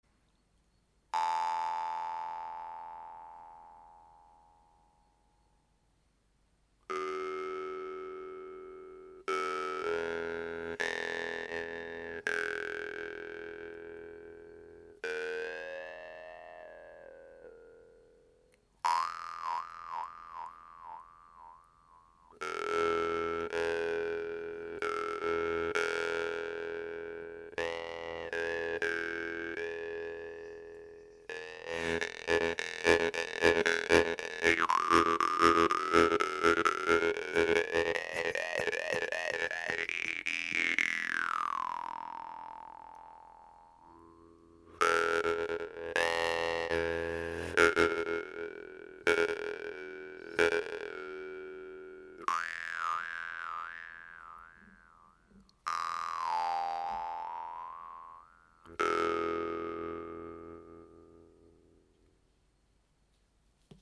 Les tonalités de ces guimbardes se situe en majorité dans l’octave 1 et offrent donc des sons assez bas et profond, d’où un sustain très appréciable. Idéale pour un jeu méditatif mais également pour monter dans les tours, le jeu avec le souffle lui va parfaitement!!